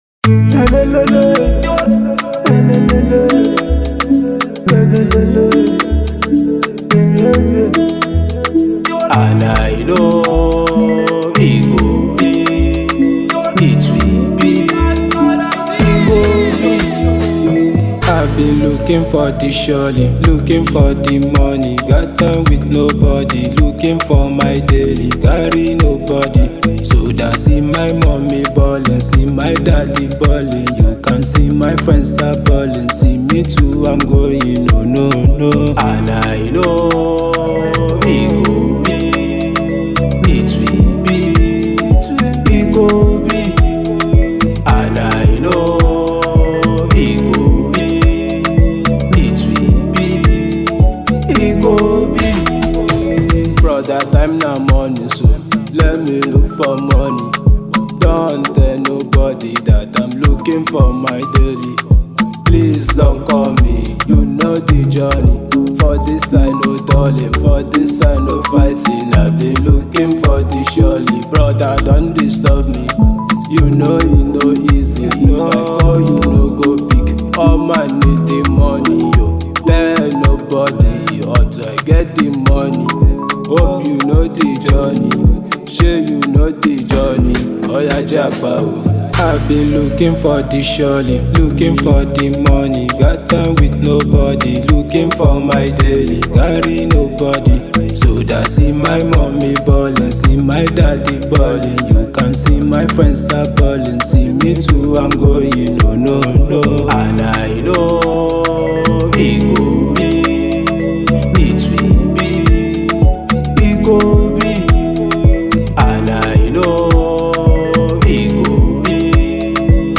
fast hip hop